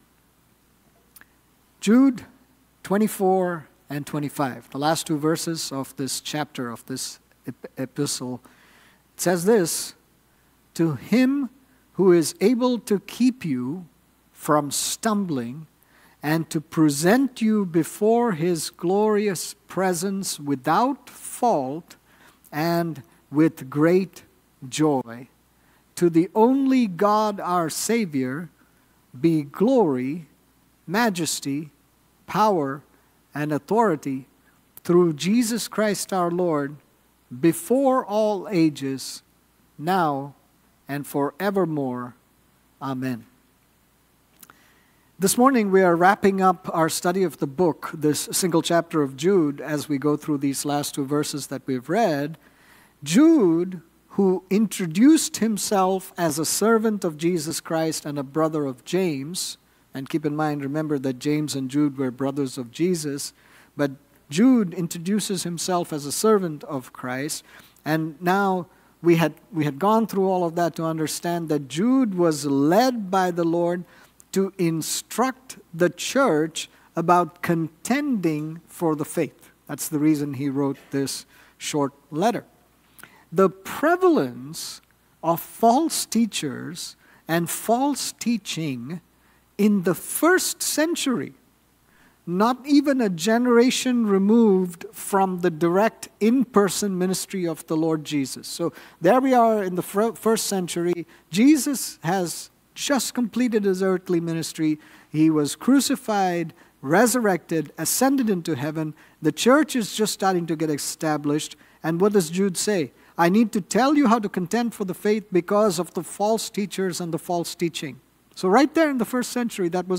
This sermon on Jude 24-25 was preached